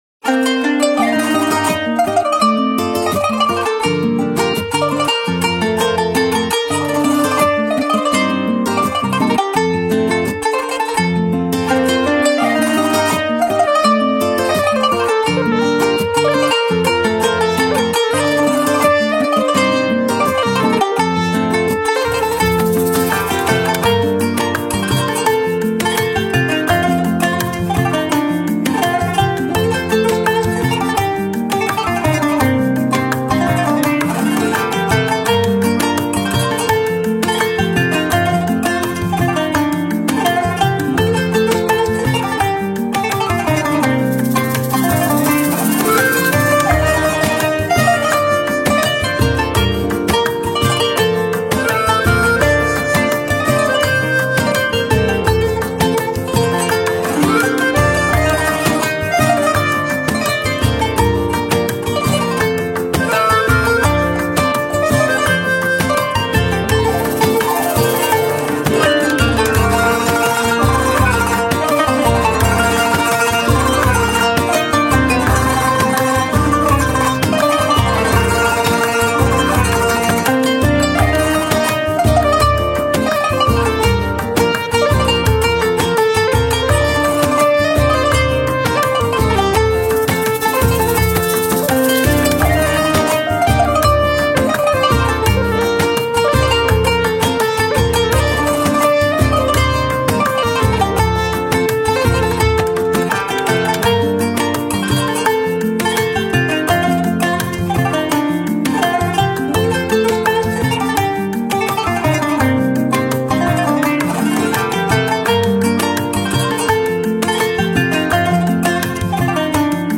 Kanun Gitar Enstrumental